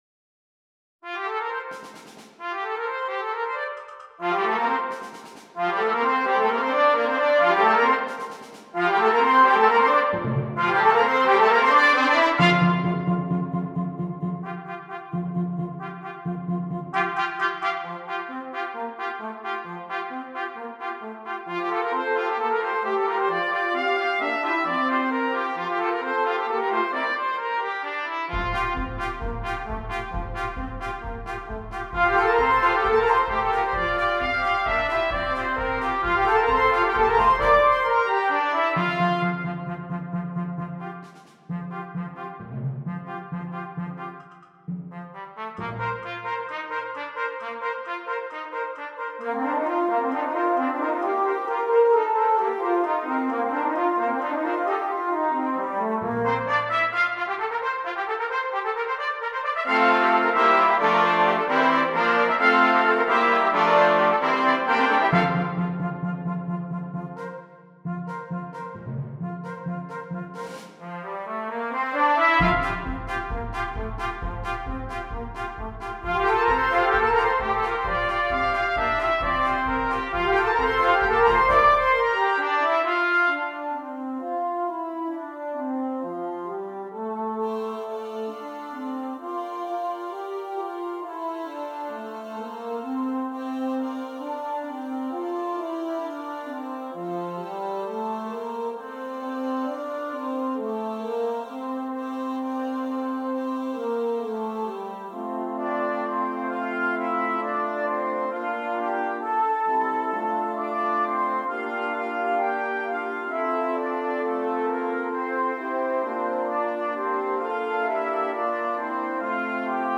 9 Trumpets and Percussion